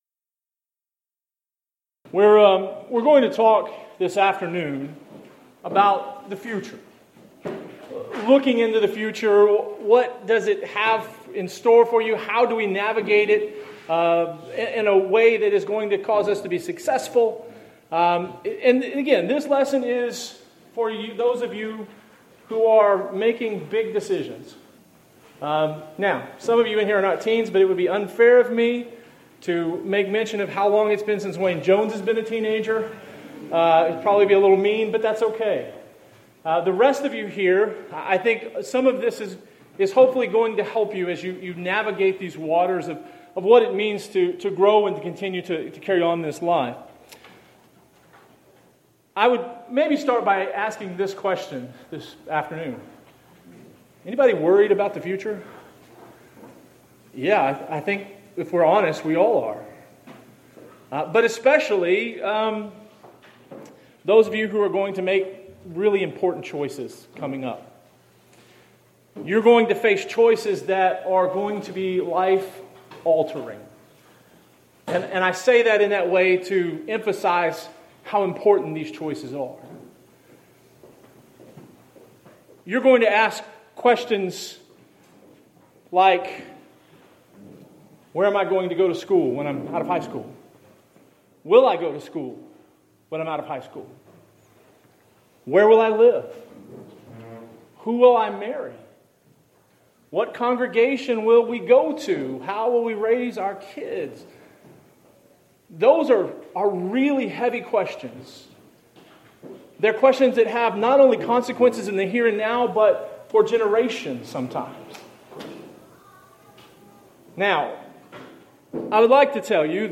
Event: 4th Annual Arise Workshop Theme/Title: Biblical Principles for Congregational Growth
Youth Sessions